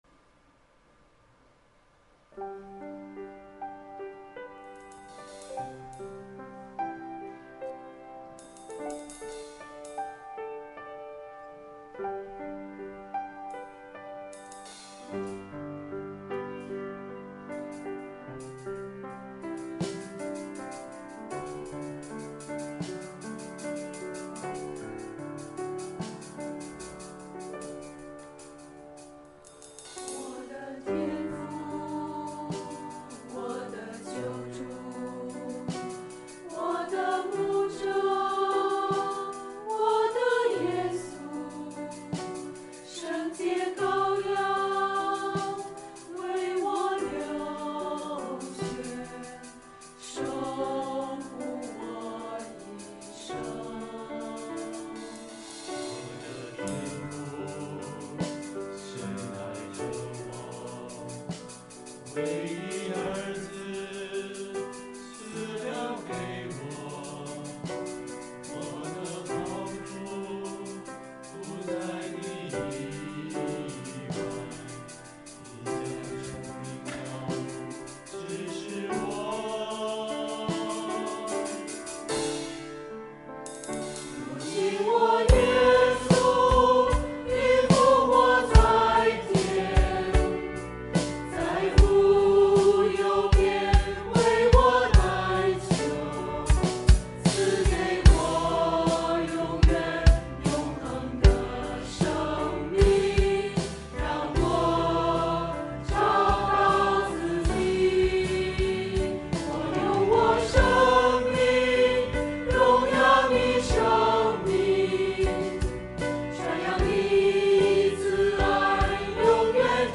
[2022年11月6日主日献唱]《我的牧者》 | 北京基督教会海淀堂
团契名称: 清泉诗班 新闻分类: 诗班献诗 音频: 下载证道音频 (如果无法下载请右键点击链接选择"另存为") 视频: 下载此视频 (如果无法下载请右键点击链接选择"另存为")